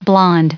blond_en-us_recite_stardict.mp3